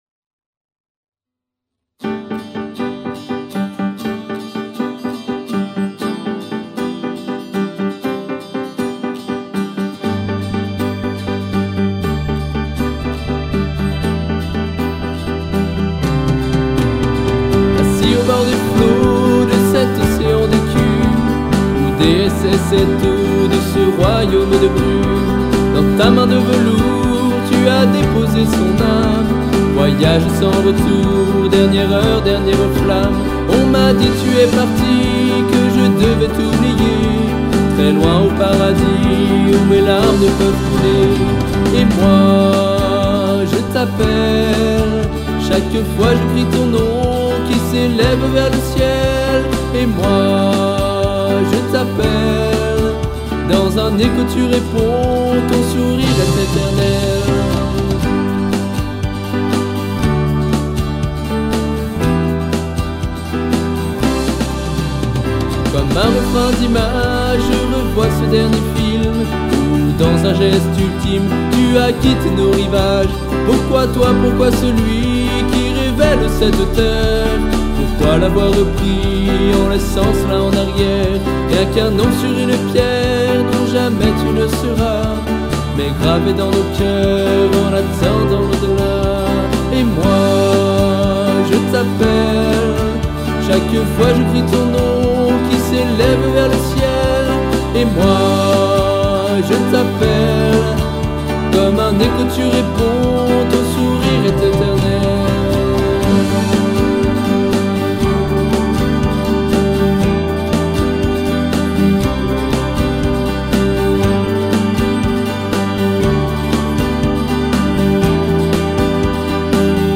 Pas de prises en multpistes, j'ai fait enregistré les pistes les unes à la suite des autres...
Quand tu parles d'un mix "au casque", sache que tu n'as pas complètement tort car j'ai commencé au casque, mes enceintes étant HS. j'ai ensuite essayé de corriger les imperfections liées au port du casque.
Un micro TBone pour la gratte et la voix
Le reste des instrus sont des pistes midi dont j'ai chopé les sons sur le Reason spécial protools...à part l'orgue et la pseudo basse qui sort d'un expandeur JV1080.